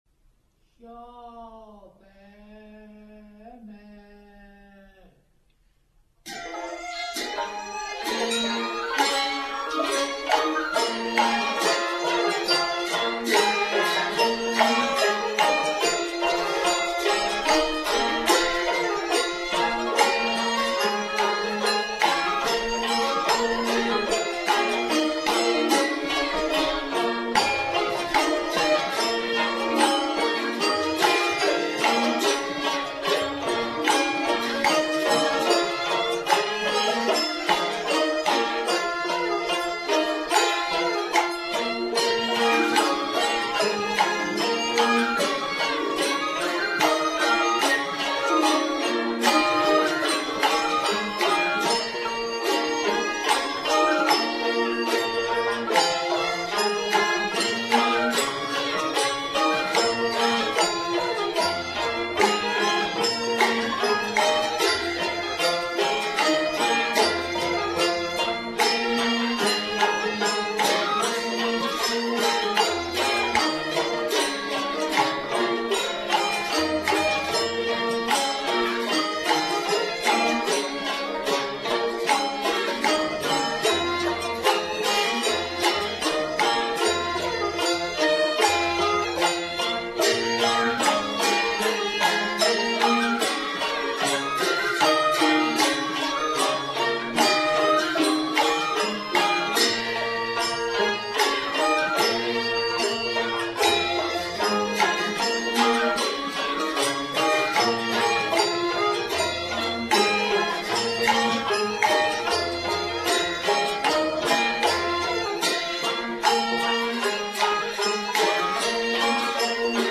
很古朴的音乐